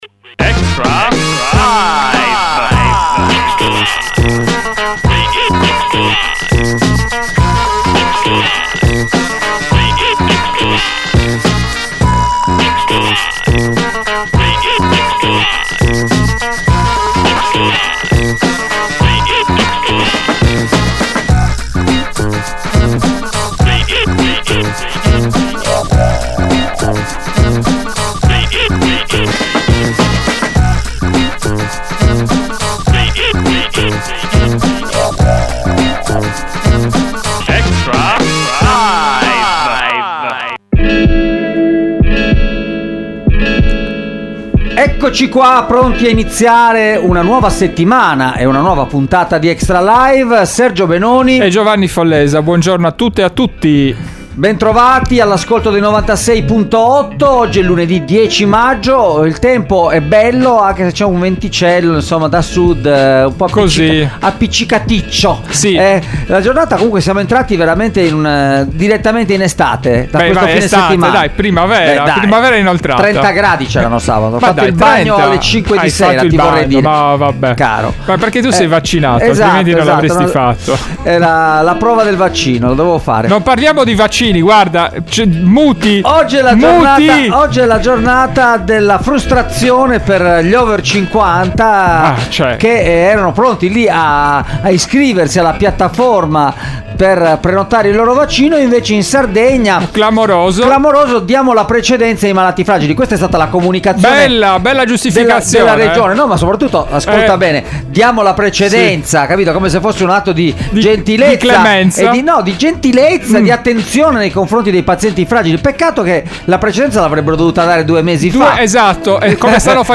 Extralive mattina: ogni giorno in diretta dalle 8 alle 9 e in replica dalle 13, il commento alle notizie di giornata dalle prime pagine dei quotidiani con approfondimenti e ospiti in studio.